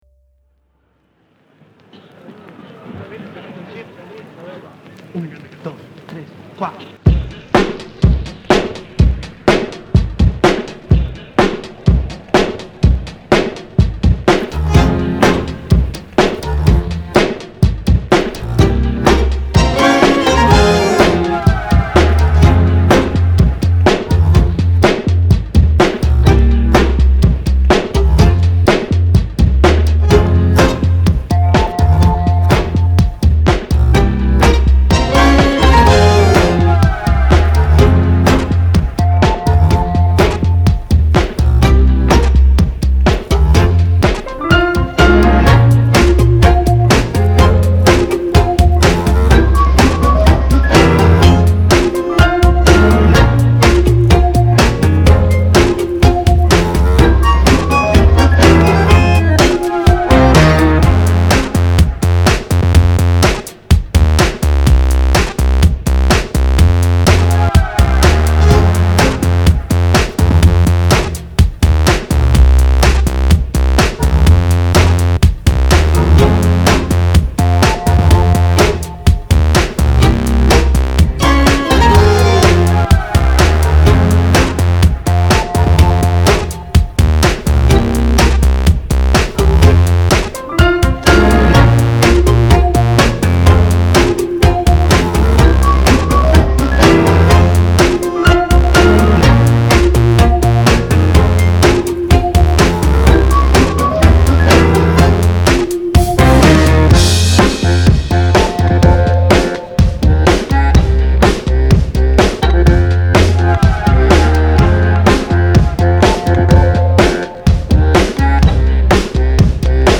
It’s drama you can dance to.